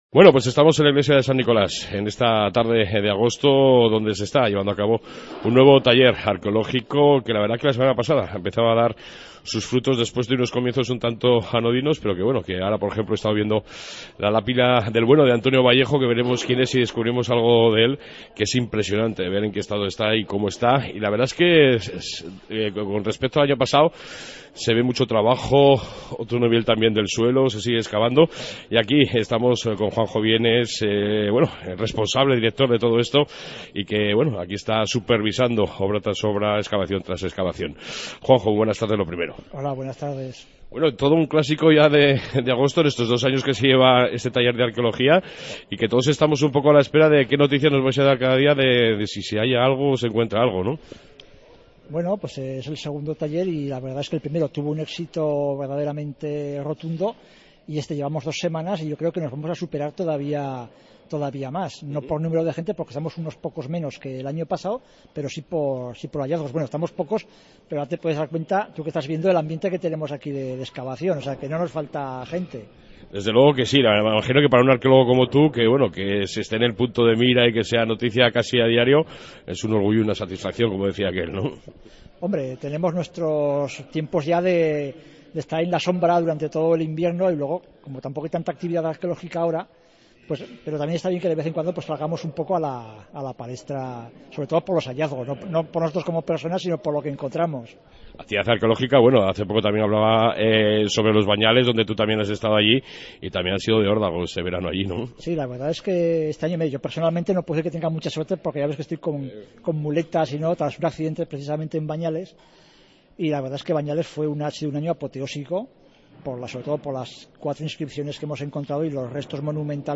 Ayer pase una tarde estupenda en las excavaciones arqueológicas de San Nicolas y me traje un reportaje, con algunos de sus protagonistas, de esta magnifica aventura y experiencia que nos descubre muchísimas cosas cada agosto desde el año pasado.